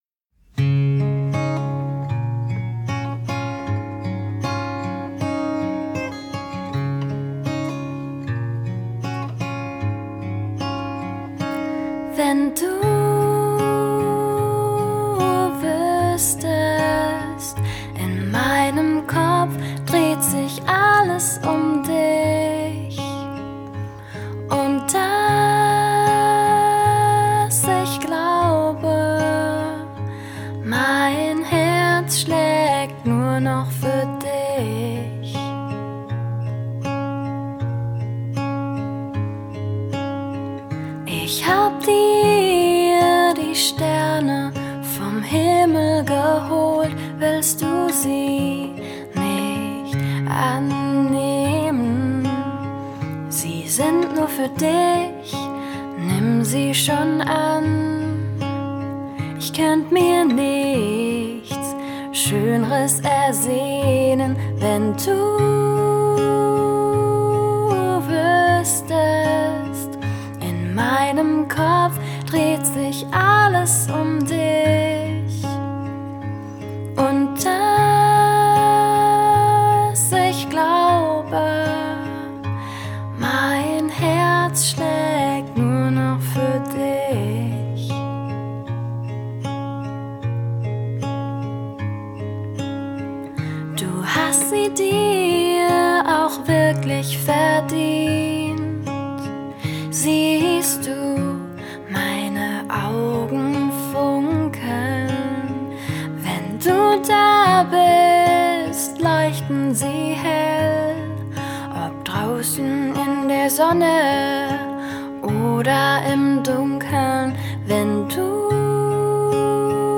Singer Songwriter Pop